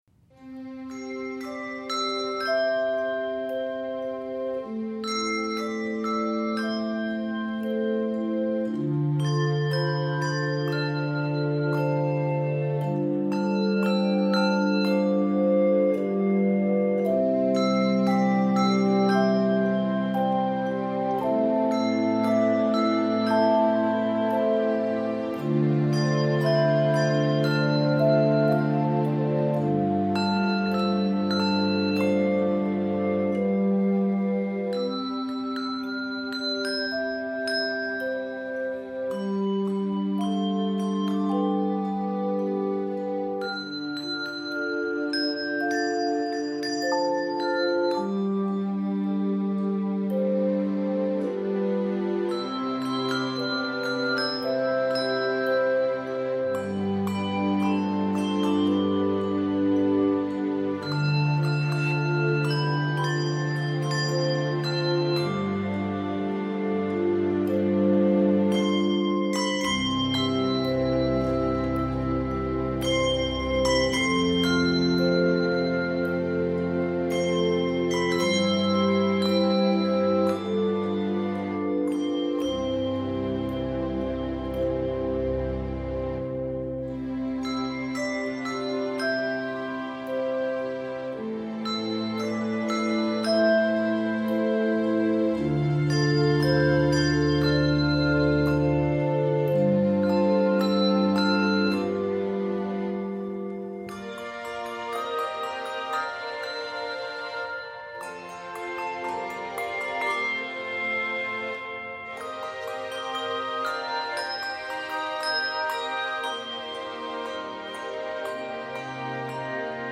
contemporary handbell setting
the flowing, graceful style
Key of C Major. 61 measures.